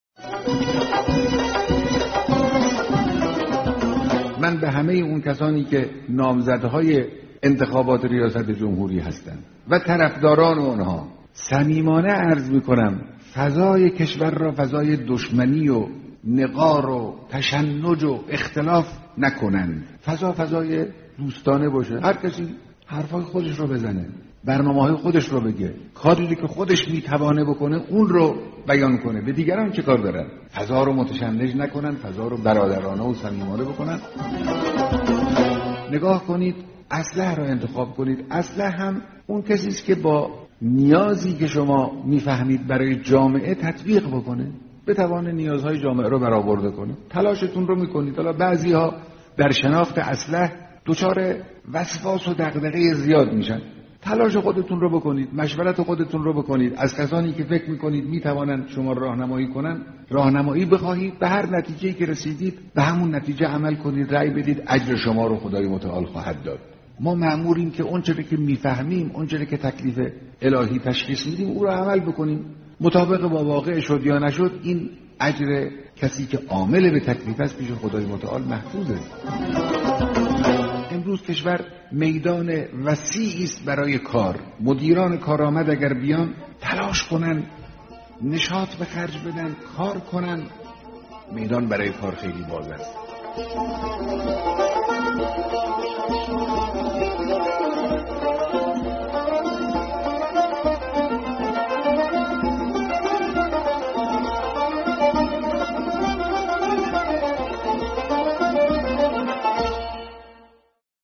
کلیپ صوتی از بیانات رهبر انقلاب در مورد انتخابات / وظیفه‌ی مردم